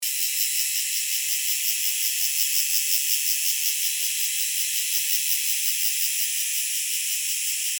2012金山魚路古道青面姬春蟬.mp3
青面姬春蟬 Euterpnosia viridifrons
台北市 北投區 陽明山魚路古道
錄音環境 森林
雄蟬鳴唱